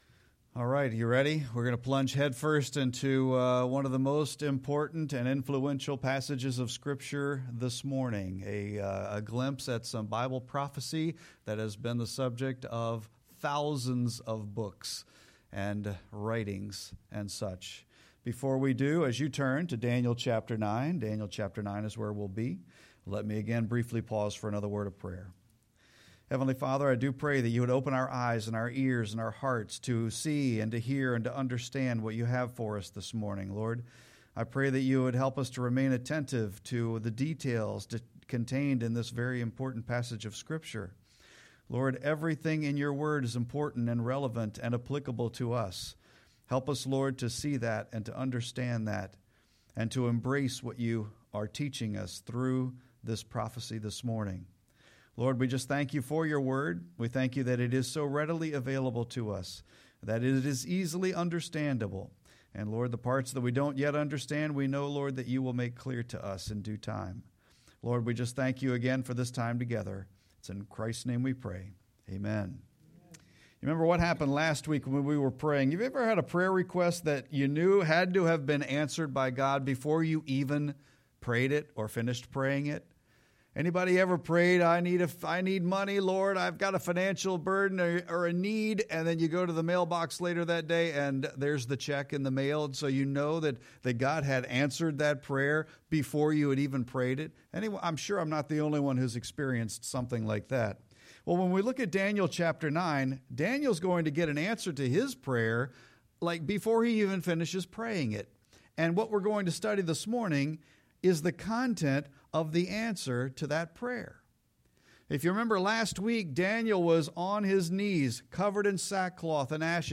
Sermon-2-2-25.mp3